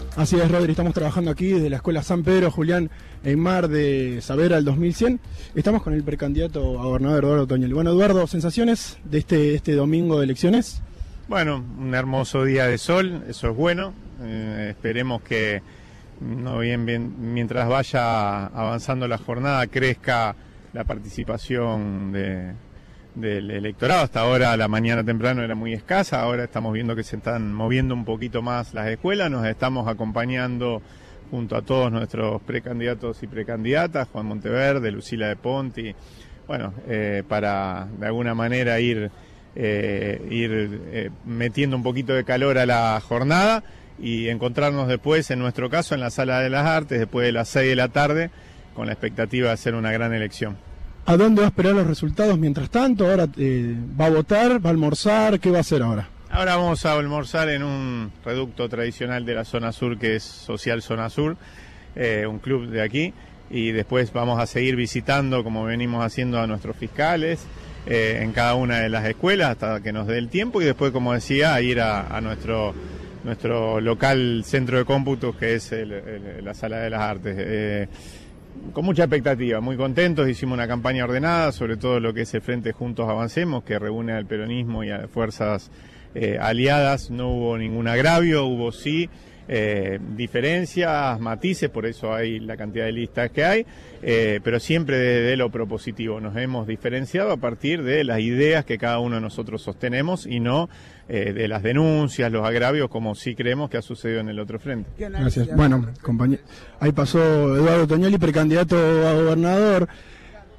El precandidato a gobernador en la interna del peronismo y fuerzas aliadas habló con Cadena 3 Rosario después de votar.
Audio. Eduardo Toniolli habló en Cadena 3 Rosario tras votar en las PASO.